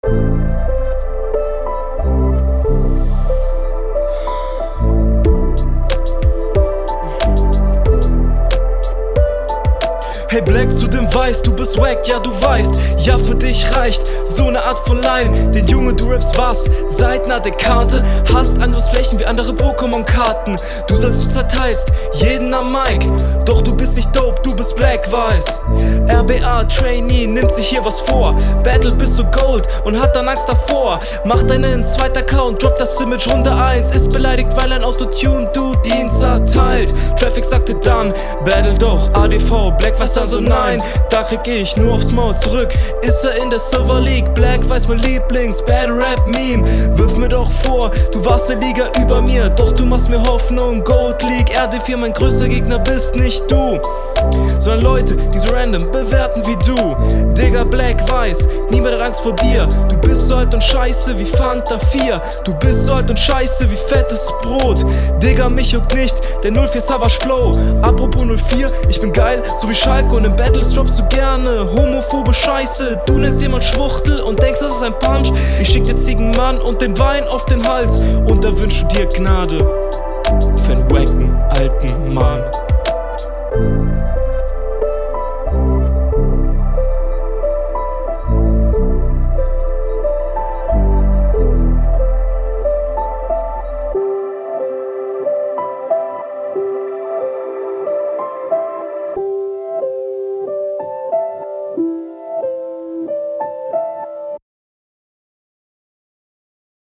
Flow: komplett undurchschaubare Flows und Betonungen.
auf deinem Beat bist du viel mehr on-point aber deine Patterns sind langweiliger als in …